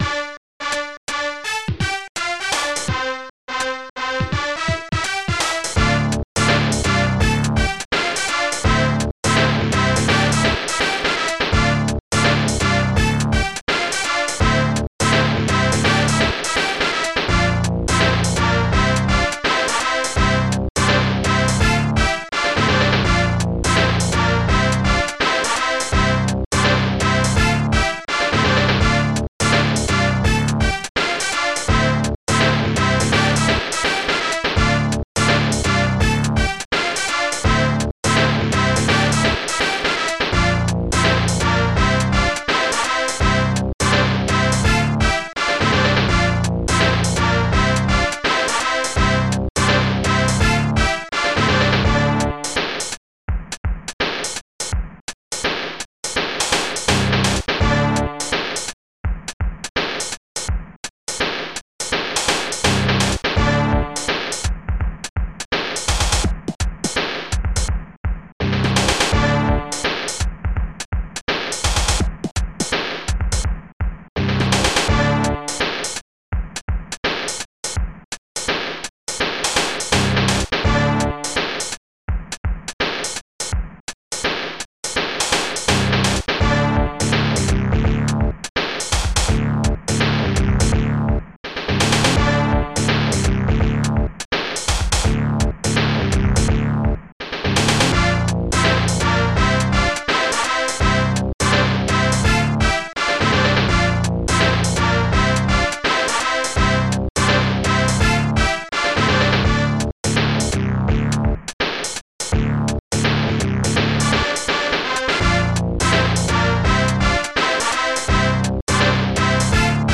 st-12:acidbass
st-01:bassdrum
st-01:hihat1
st-01:snaredrum
st-01:trompet